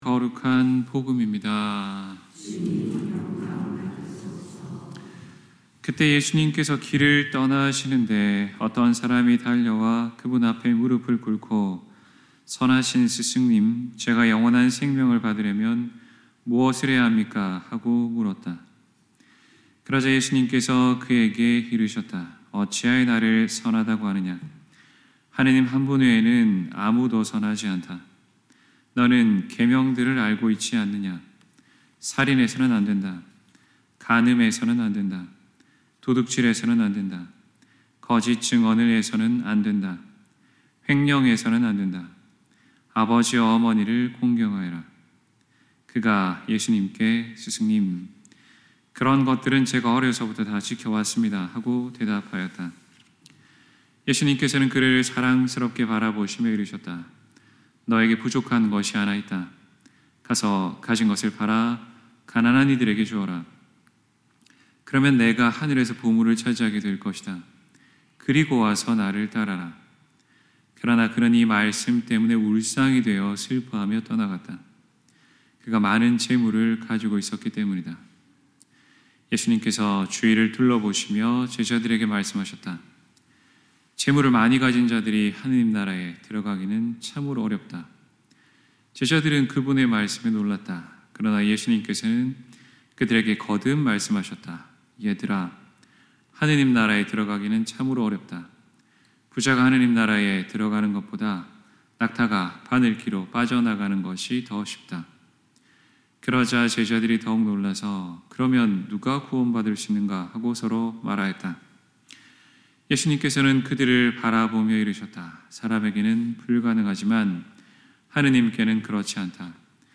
2024년10월 13일 연중 제28주일 신부님 강론